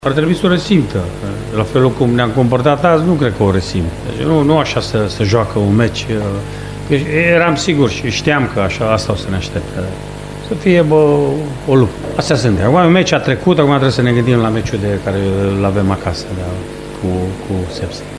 Rednic-jucatorii-ar-trebui-sa-simta-presiunea.mp3